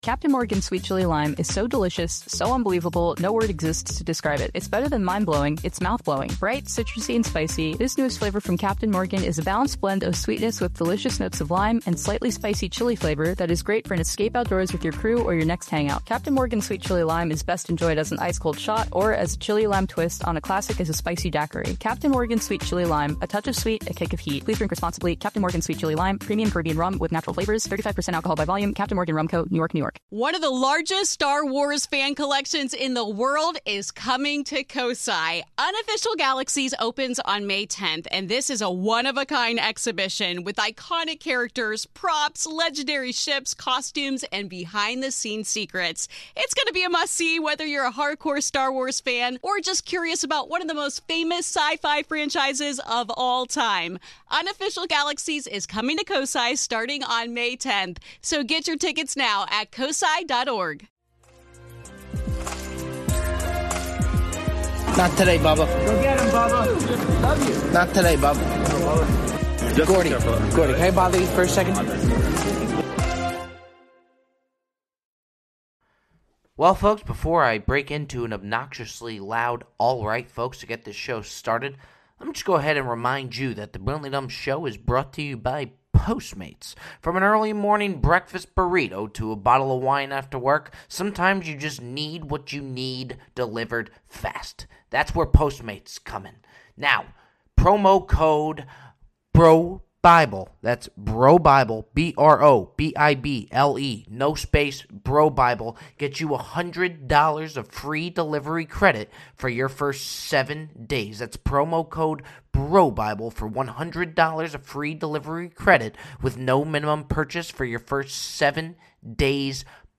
GaTa the rapper sits down to discuss his new hit show “Dave”, and what life on tour was like with Lil Wayne.